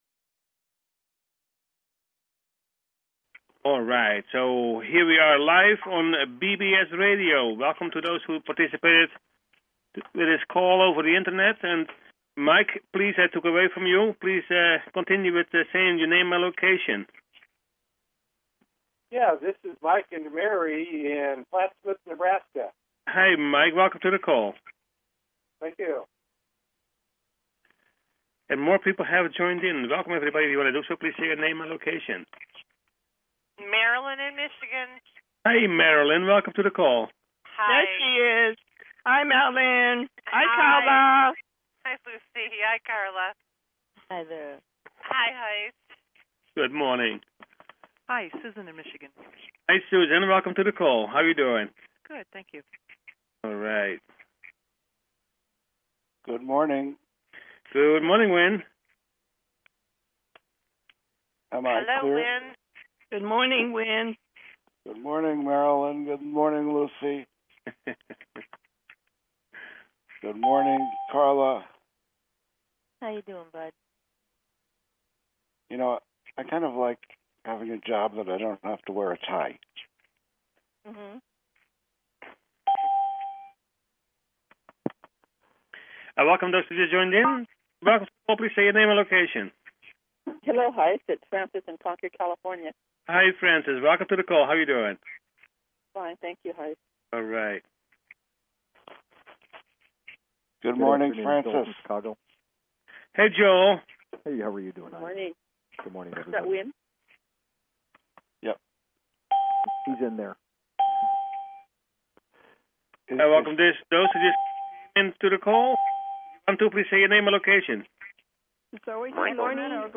Talk Show Episode, Audio Podcast, Personal_Planetary_Healing_Meditation and Courtesy of BBS Radio on , show guests , about , categorized as